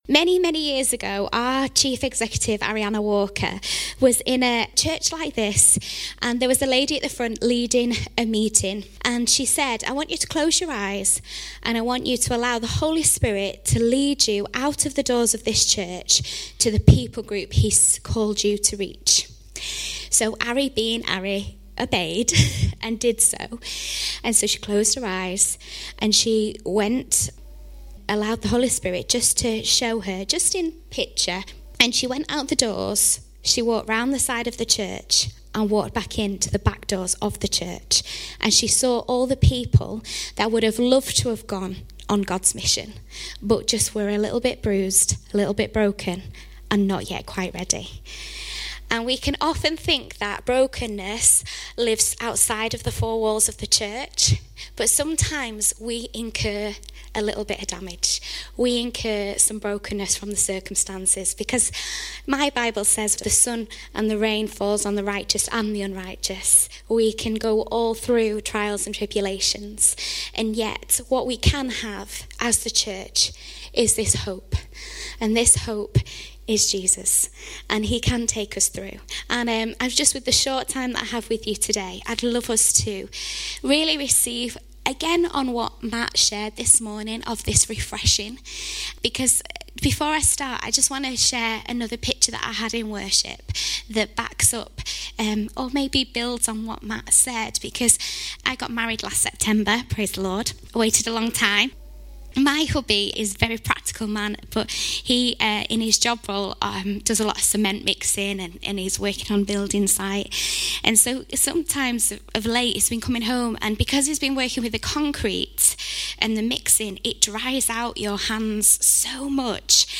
1-July-2018-sermon.mp3